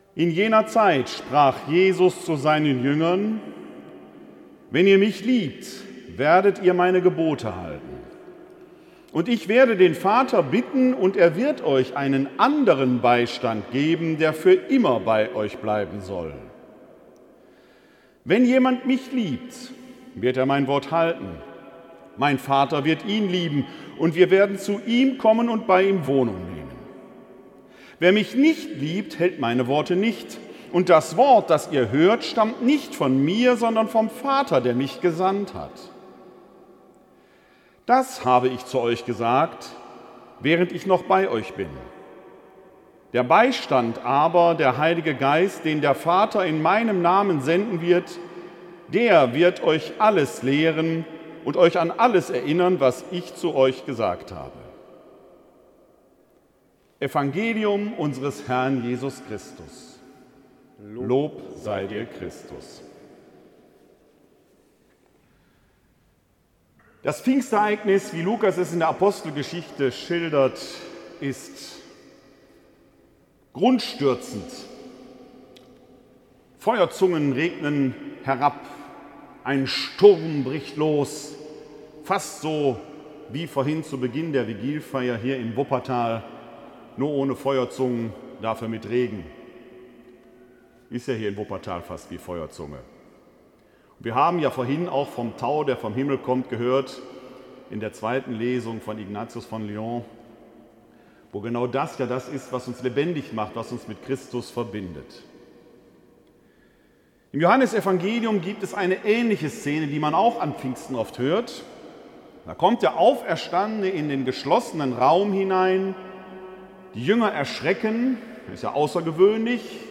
Mitschnitt der Homilie